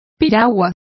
Complete with pronunciation of the translation of dugout.